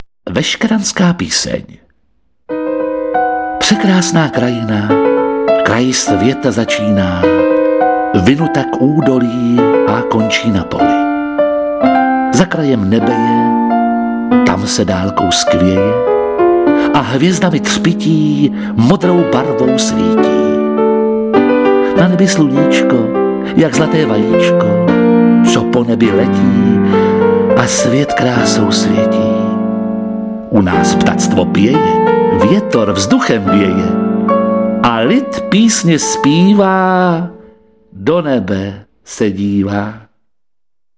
Pábitel a předchůdce Járy Cimrmana Jakub Hron Metánovský v mistrovském podání legendy českého divadla, doplněno i audio záznamem divadelní inscenace (CD; 66:31).